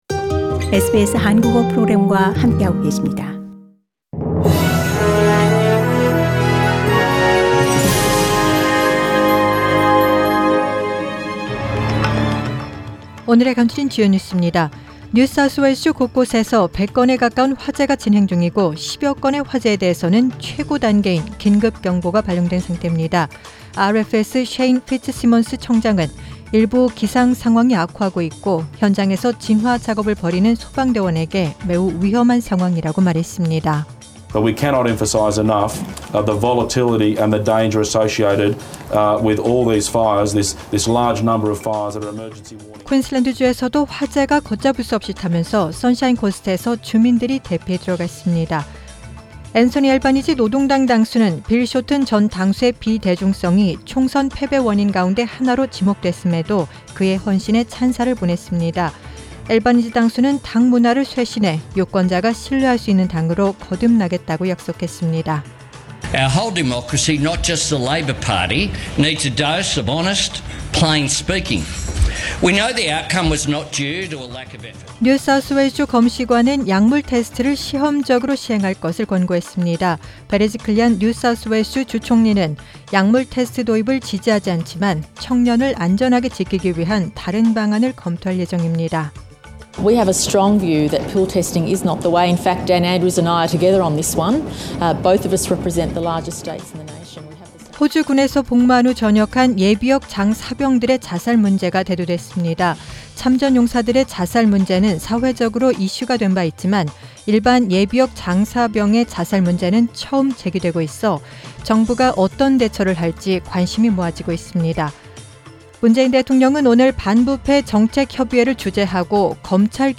Major stories from SBS Korean News on Friday, 8 November